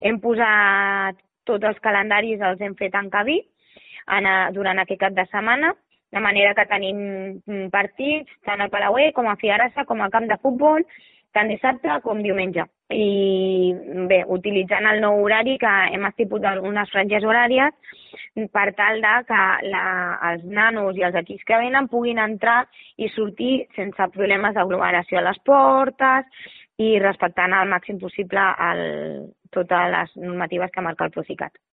Per part de l’Ajuntament, la regidora d’Esports, Sònia González, ha explicat als micròfons del programa Minut a Minut que la trobada ha servit per revisar tot el protocol que cal tenir en compte de cara a les competicions, així com s’ha establert el document que servirà per controlar la traçabilitat en cas de contagis. Segons ha explicat González, es farà una fitxa tècnica adaptada a les necessitats de les diferents disciplines esportives.